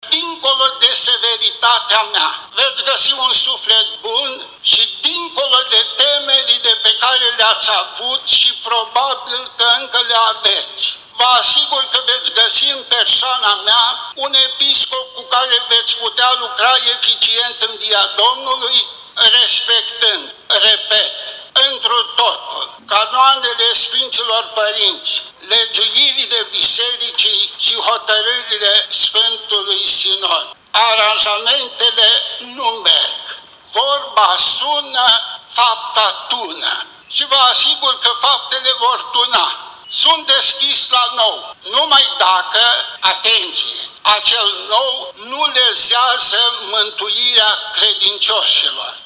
Slujba la care a participat un număr limitat de credincioși s-a desfășurat în curtea lăcașului de cult.
La finalul ceremoniei, CALINIC a mulțumit pentru încrederea arătată de conducerea Bisericii Ortodoxe, adăugând că va continua ”cu aceeași râvnă întărirea bisericii de zid și a celei din sufletul fiecăruia”.